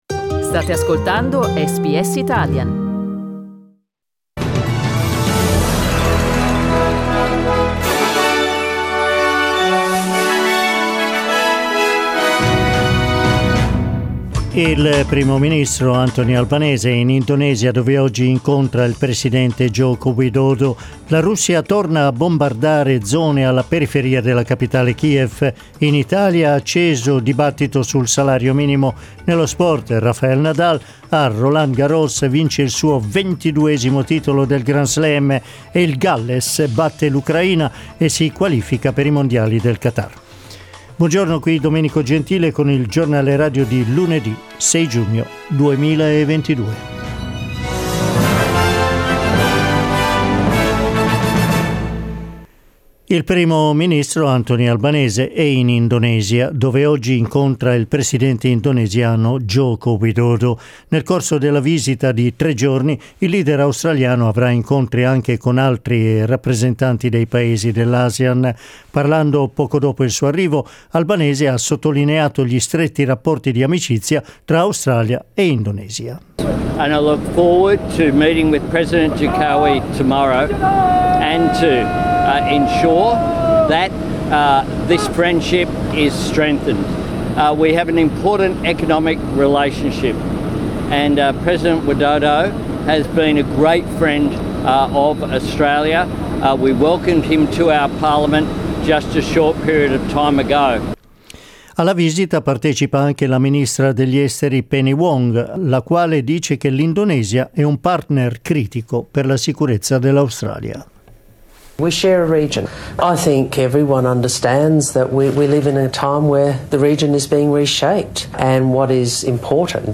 Giornale radio lunedì 6 giugno 2022
Il notiziario di SBS in italiano.